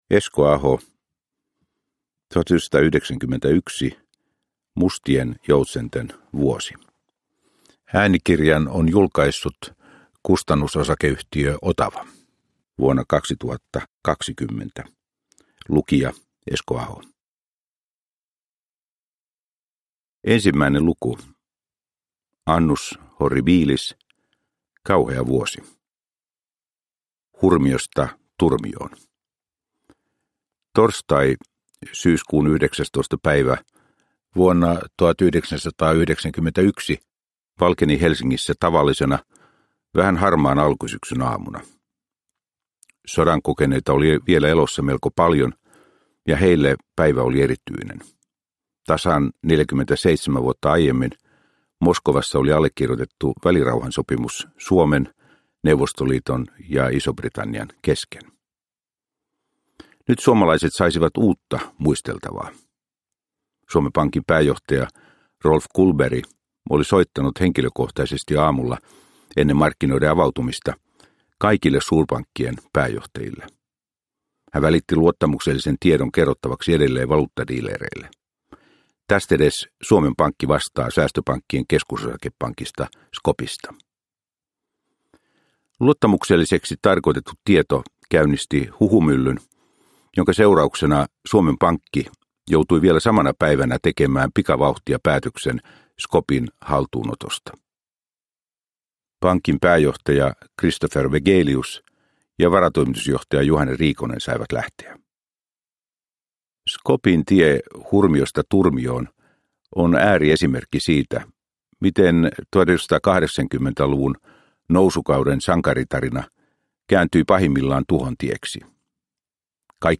1991 – Ljudbok – Laddas ner
Uppläsare: Esko Aho